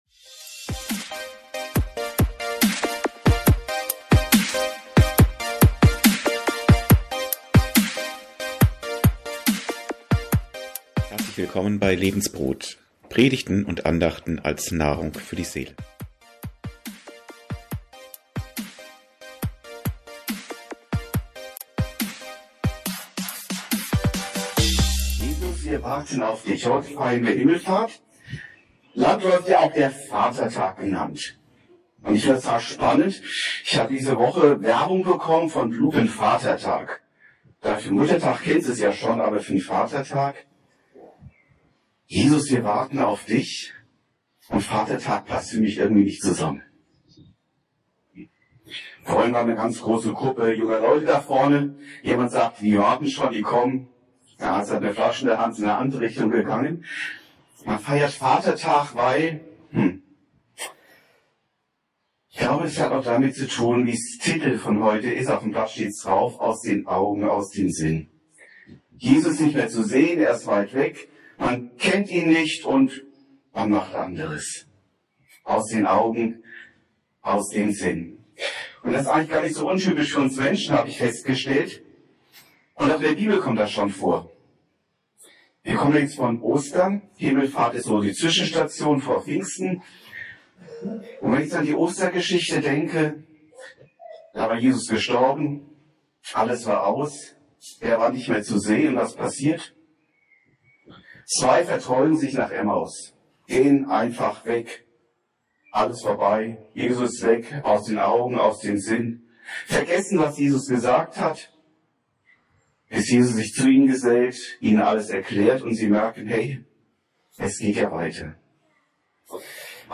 120 Gäste feierten mit beim Open Air - Familiengottesdienst in Uelsen.
Bedingt durch Openair ist die Aufnahme nicht so optimal.